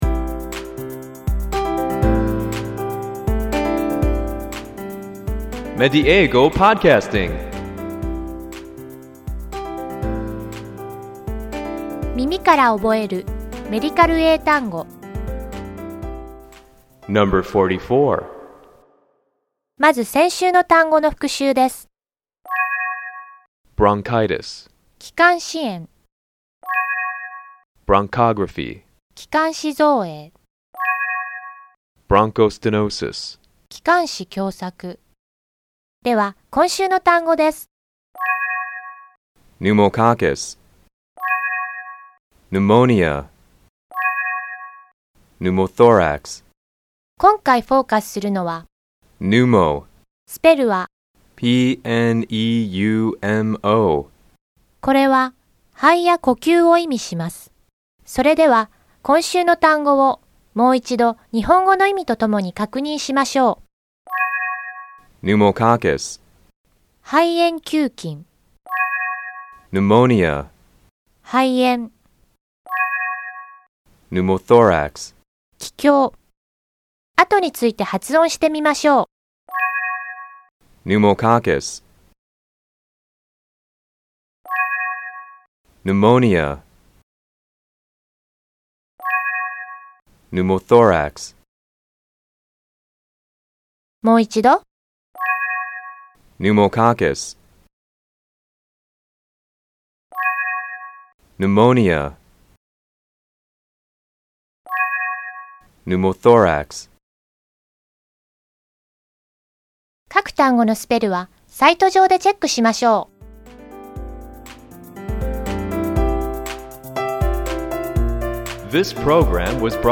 ネイティブの発音を聞いて，何度も声に出して覚えましょう。